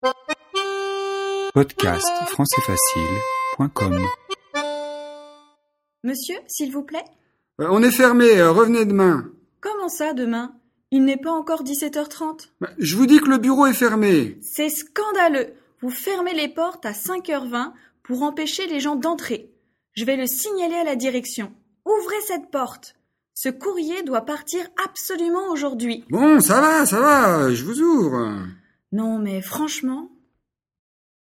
Dialogue FLE et exercice de compréhension, niveau intermédiaire (A2) sur un thème de la vie quotidienne.